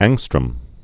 (ăngstrəm, ôngstrœm), Anders Jonas 1814-1874.